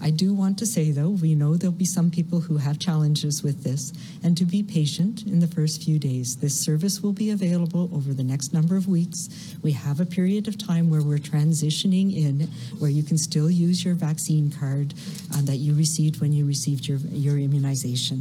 – Dr. Bonnie Henry – Provincial Health Officer